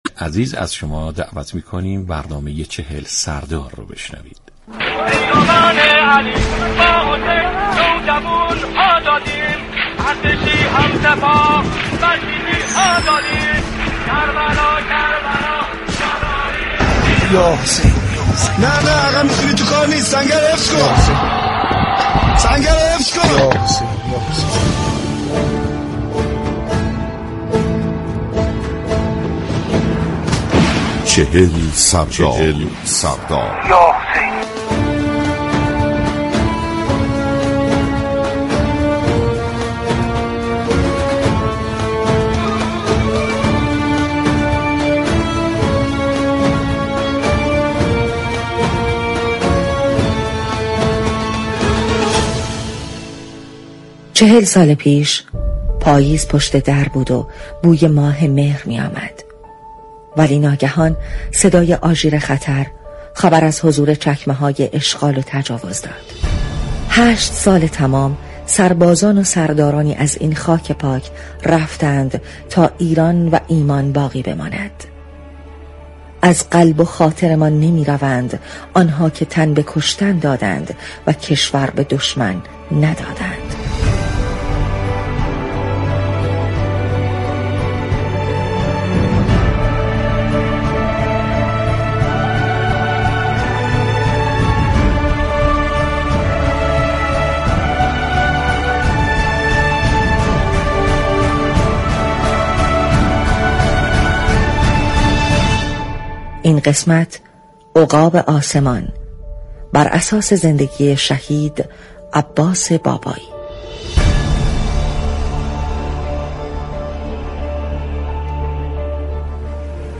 برنامه "چهل سردار" ویژه برنامه ای به مناسبت هفته دفاع مقدس است كه هر شب ساعت 20:30 به مدت 25 دقیقه از شبكه رادیویی ورزش پخش می شود.